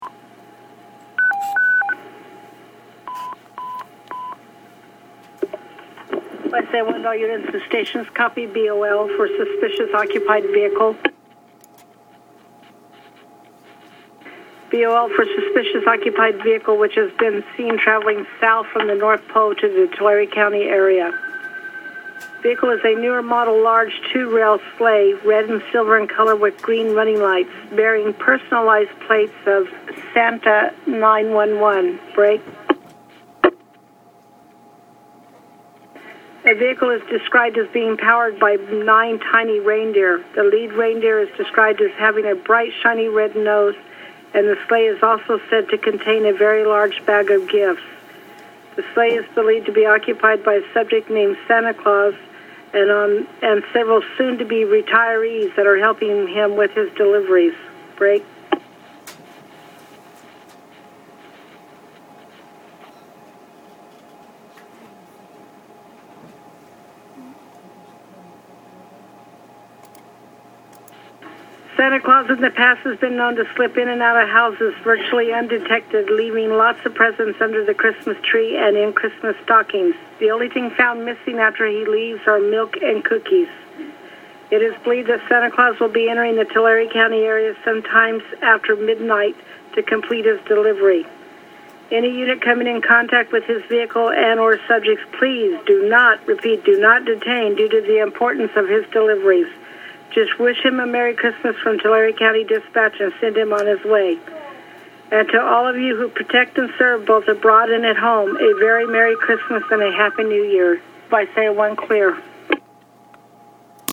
Here’s our Santa BOL (be on the lookout) broadcast for 2012.